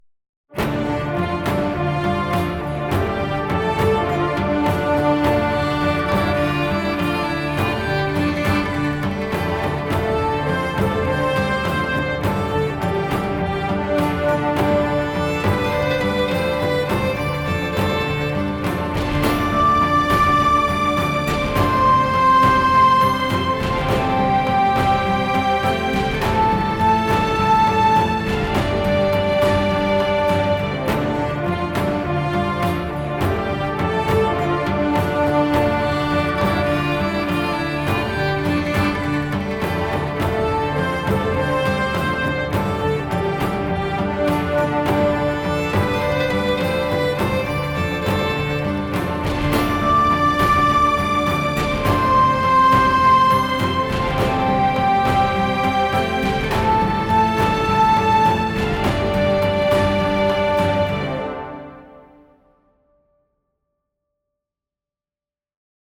Orchestral track for western and RPG.
Orchestral epic track for western and RPG.